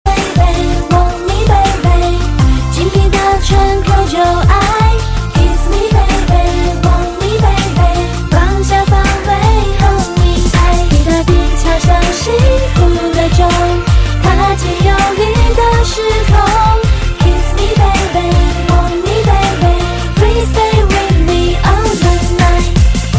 M4R铃声, MP3铃声, 华语歌曲 53 首发日期：2018-05-16 00:40 星期三